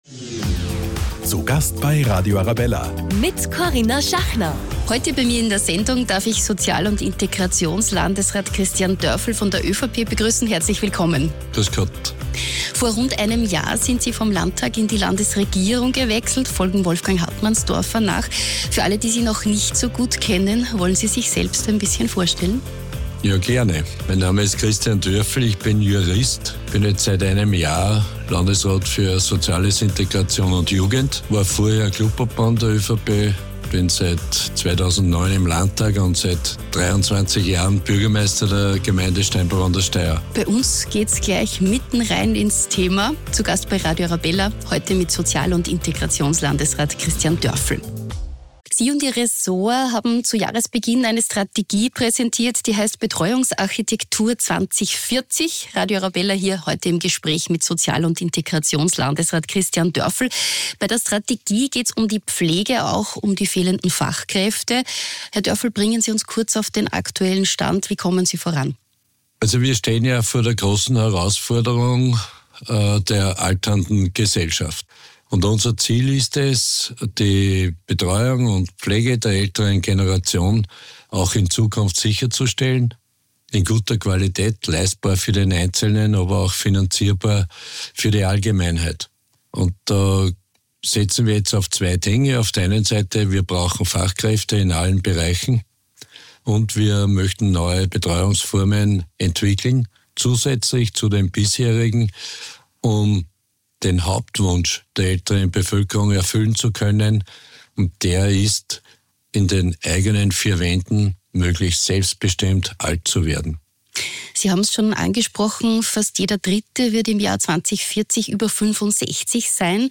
Heute zu Gast: Sozial- und Integrationslandesrat Christian Dörfel